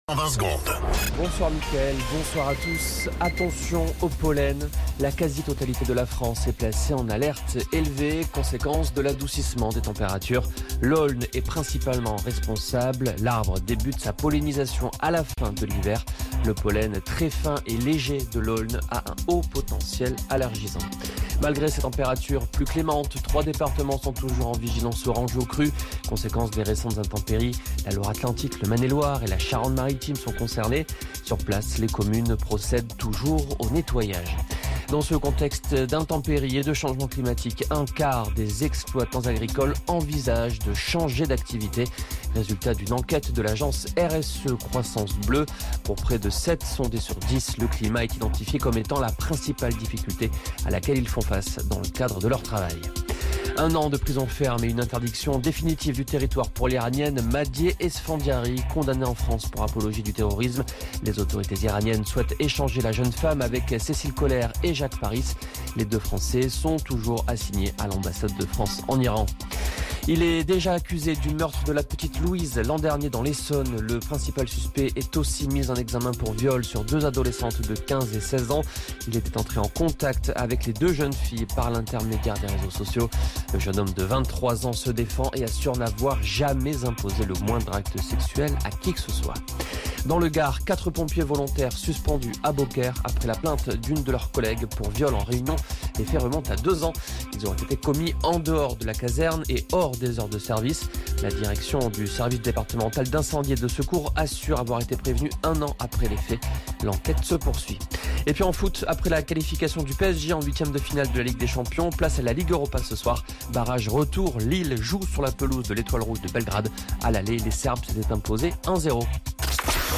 Flash Info National 26 Février 2026 Du 26/02/2026 à 17h10 .